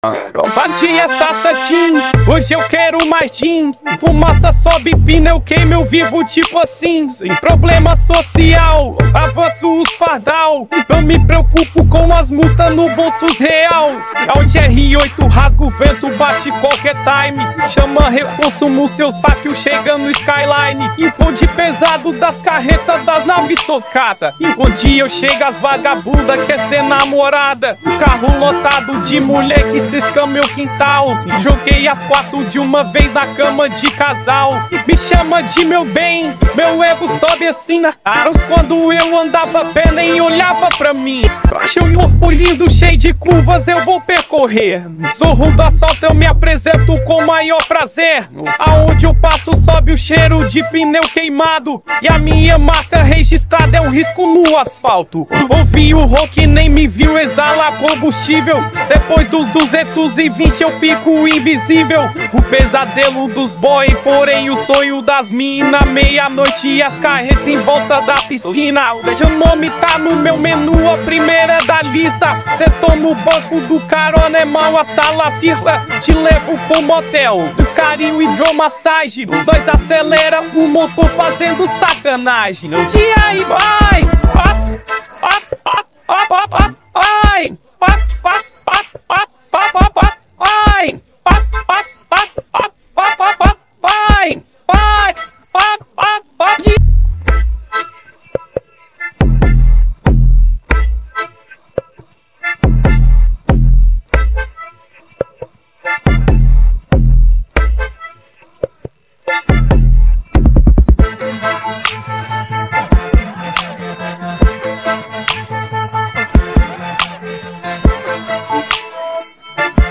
2024-10-24 21:35:01 Gênero: Hip Hop Views